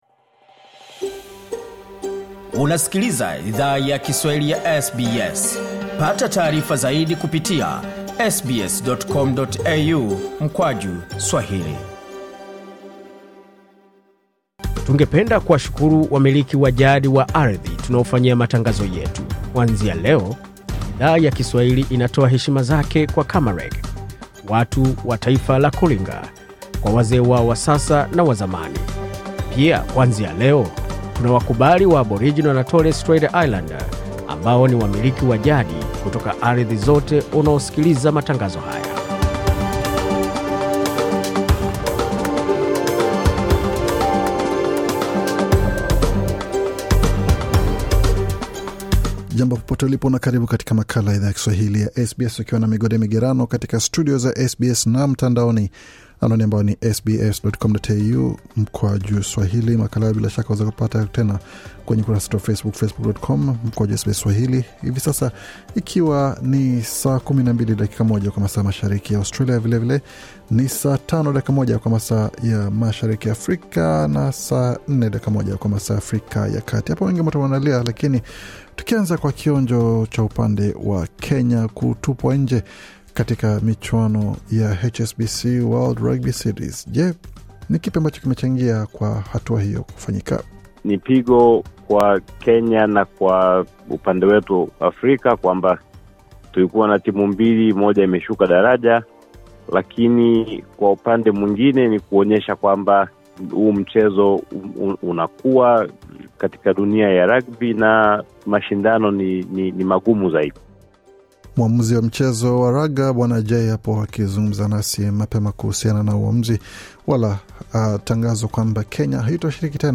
Taarifa ya Habari 23 Mei 2023